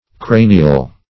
Meaning of cranial. cranial synonyms, pronunciation, spelling and more from Free Dictionary.
Cranial \Cra"ni*al\ (kr?"n?-al)